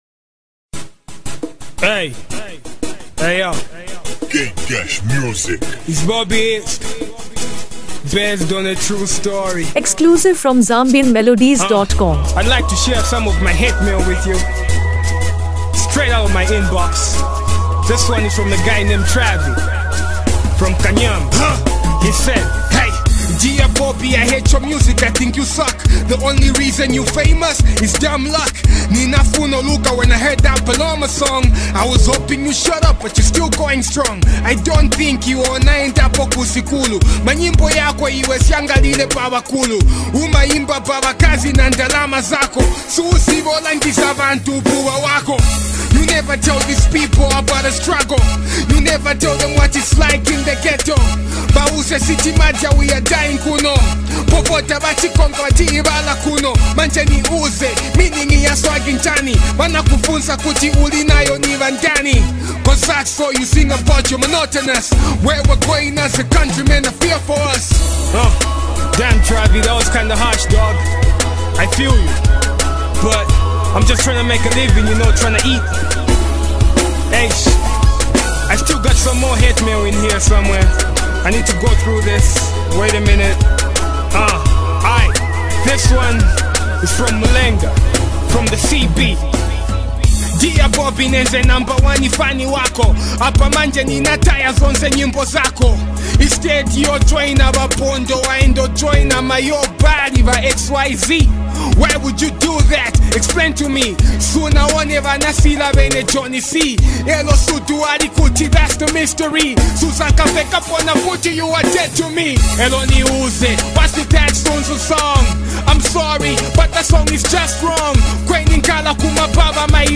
Honest Hip-Hop Expression in MP3
With a steady beat and introspective flow